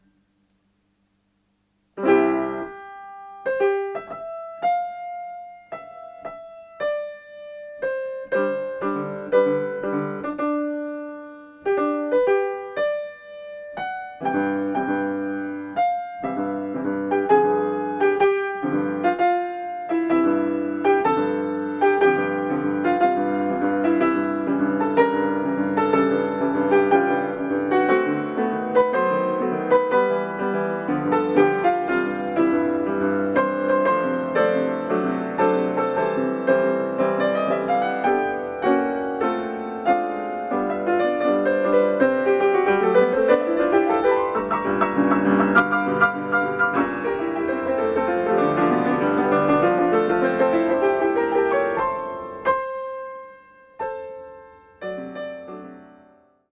＜＜＜確認のためだけの下手なmp3＞＞＞